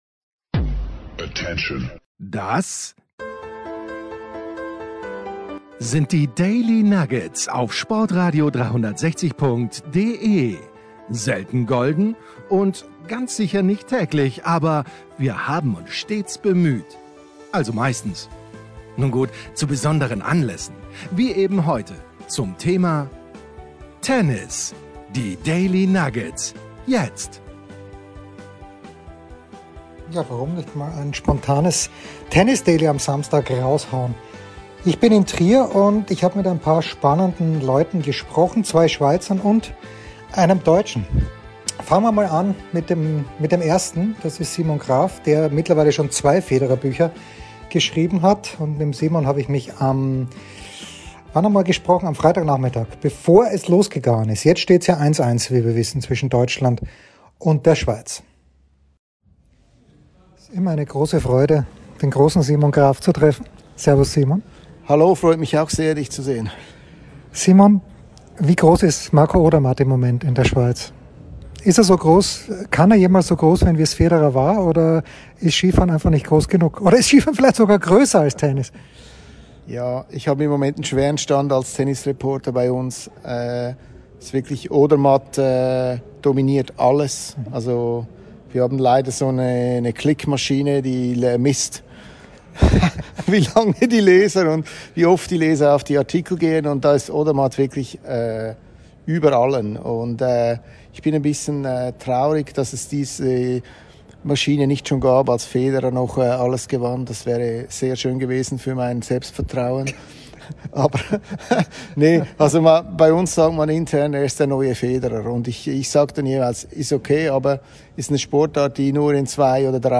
Fast ein bisschen live aus Trier